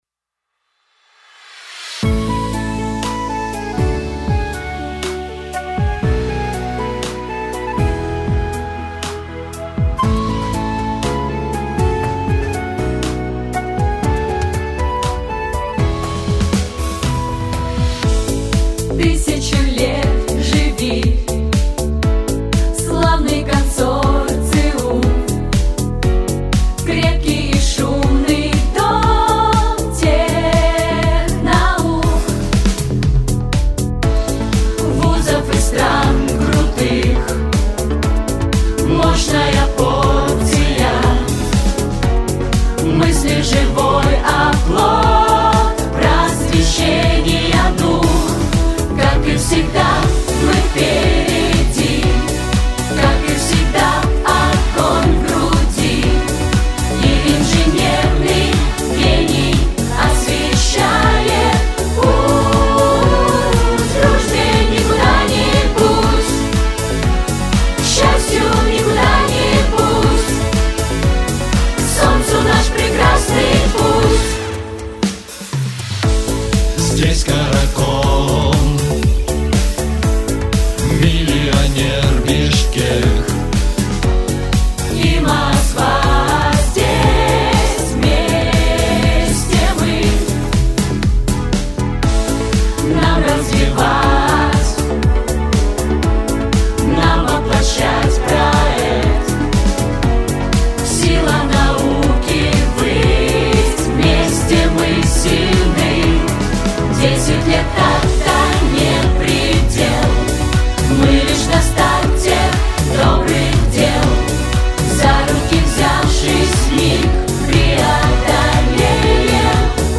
Нижневартовский государственный университет предложил проект гимна Консорциума, который тут же был исполнен.
hymn.mp3